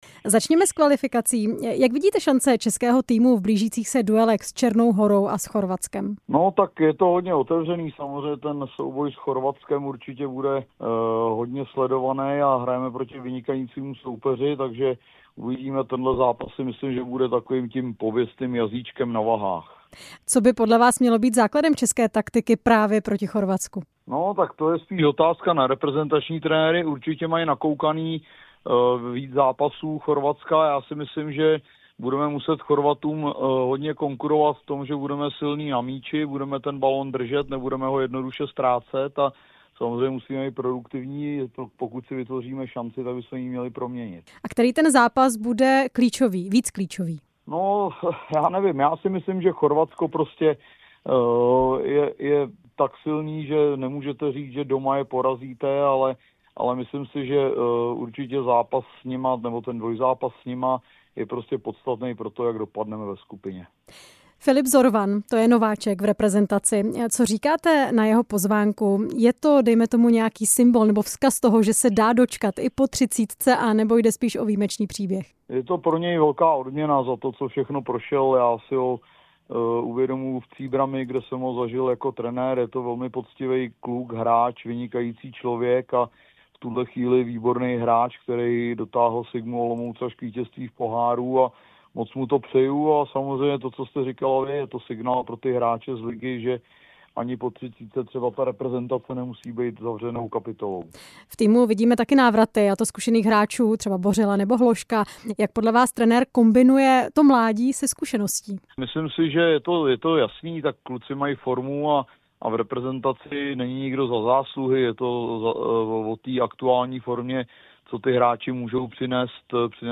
O tom jsme mluvili s bývalým reprezentačním záložníkem a trenérem Pavlem Horváthem.
Rozhovor s bývalým reprezentačním záložníkem a trenérem Pavlem Horváthem